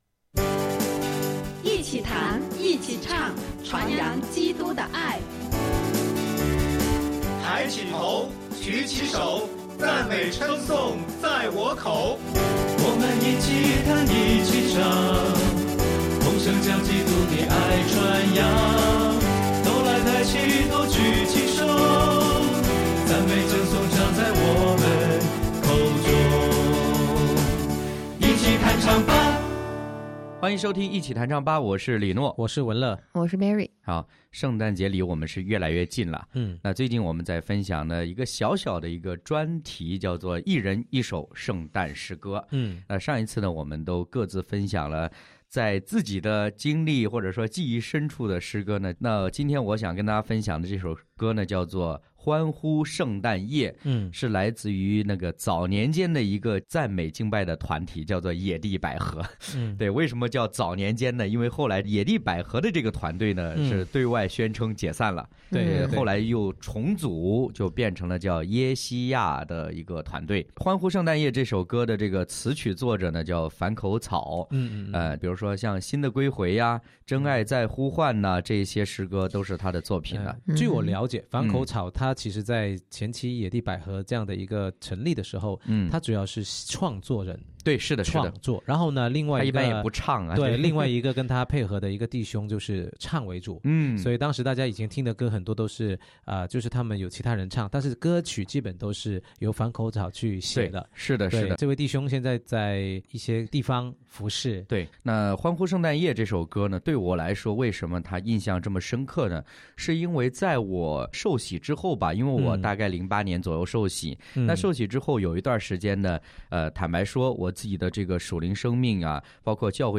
一人一首圣诞歌：《欢呼圣诞夜》、《普世欢腾》、Mary Did You Know（《玛丽亚，你可知》）